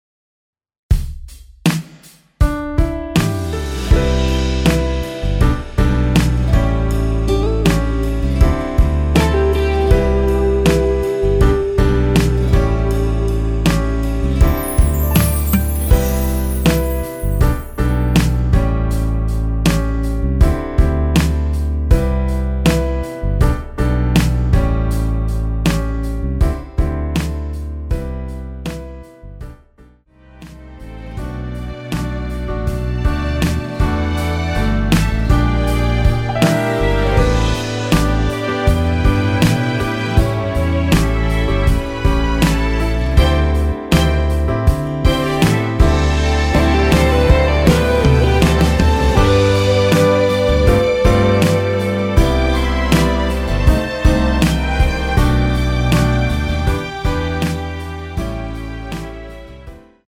원키에서 (+3)올린 MR 입니다.(미리듣기 참조)
음질 깨끗하고 좋습니다 자주 이용할께요
앞부분30초, 뒷부분30초씩 편집해서 올려 드리고 있습니다.